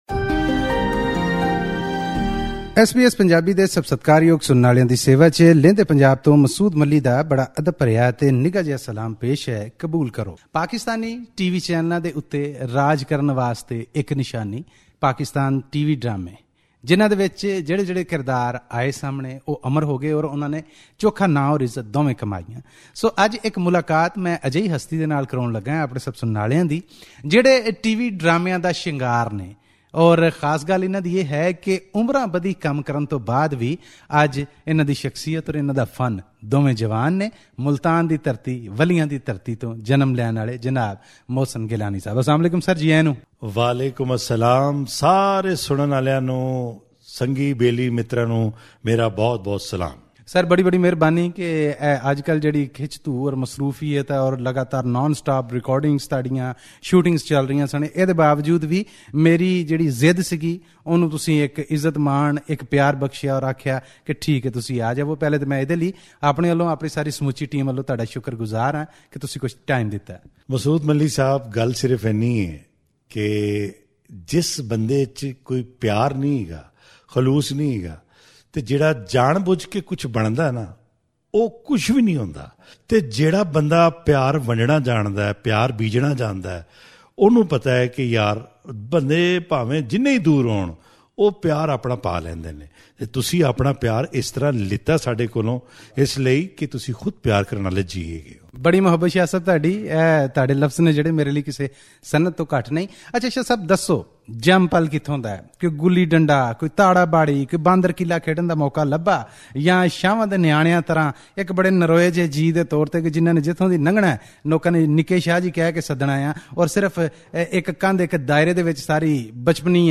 Senior actor and director Mohsin Gillani is a popular name in Pakistan’s TV and drama industry. To hear his exclusive interview with SBS Punjabi